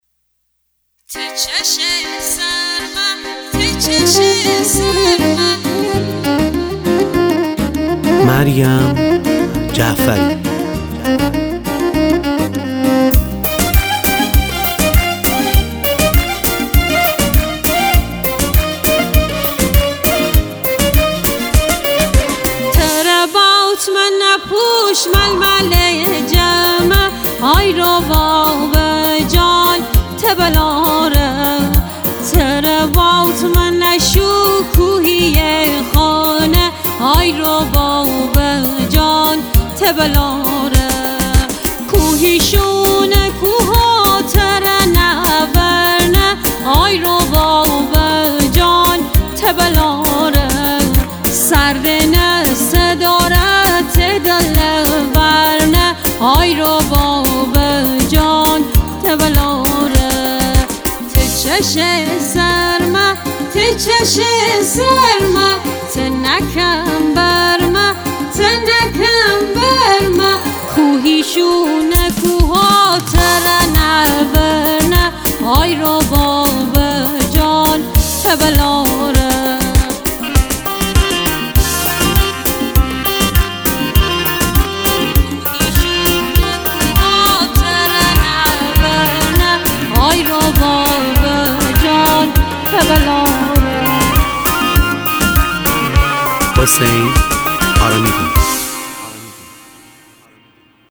شاد
آهنگ مازندرانی عید سال 1404